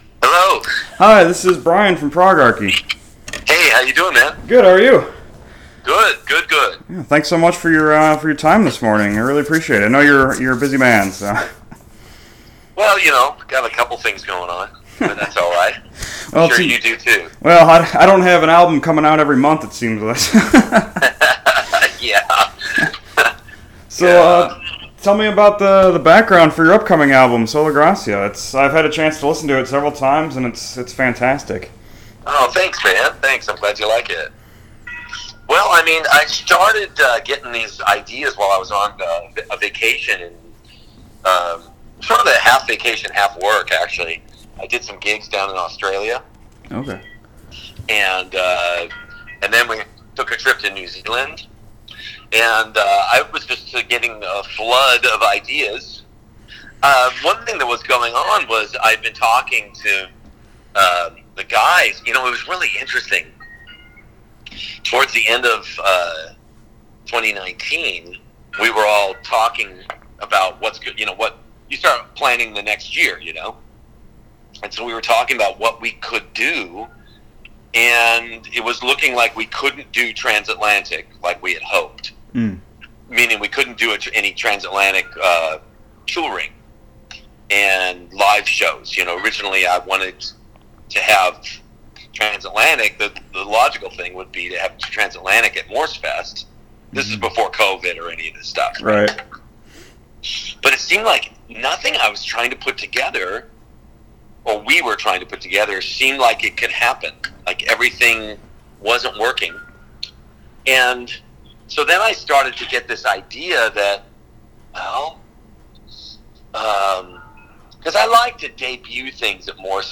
The album pulls a few lyrical and musical highlights from Sola Scriptura , but, as Morse says in the interview below, they are merely sprinklings.